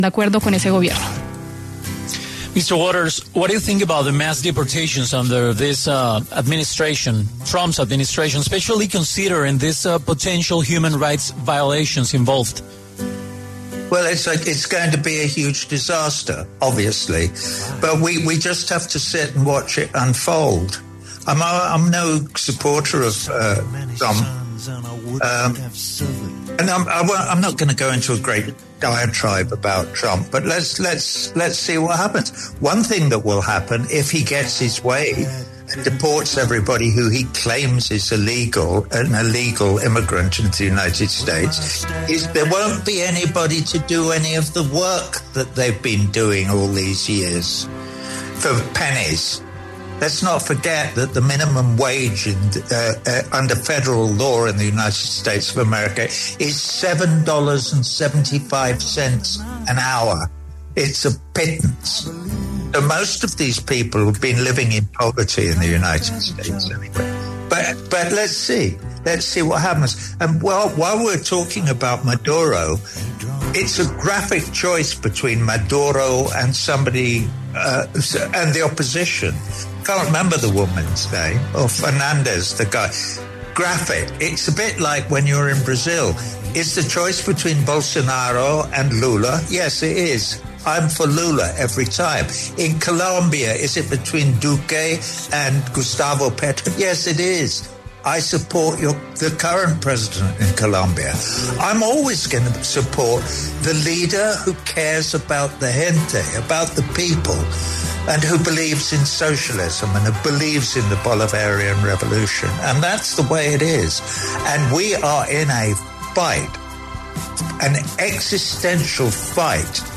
En diálogo con La W, Roger Waters, músico, y quien también lidera causas por todo el mundo, pasó por los micrófonos de La W y habló sobre la ola de deportaciones de migrantes que ha hecho Donald Trump desde Estados Unidos.